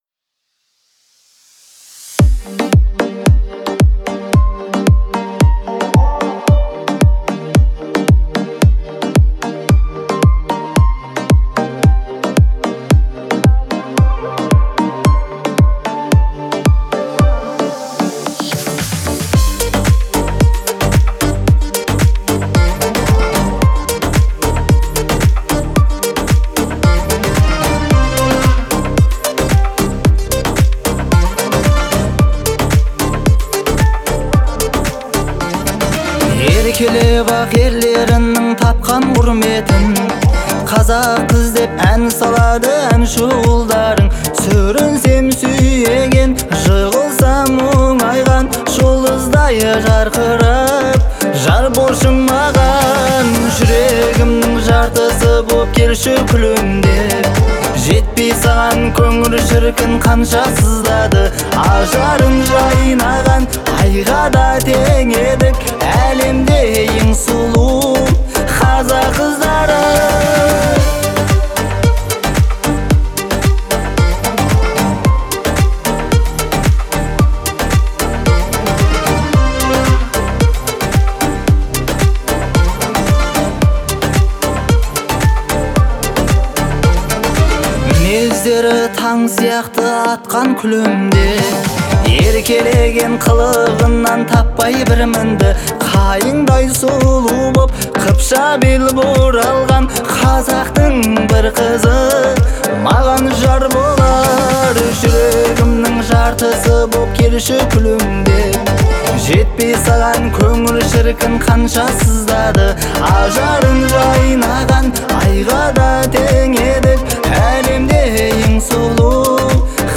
это трогательная песня в жанре казахской поп-музыки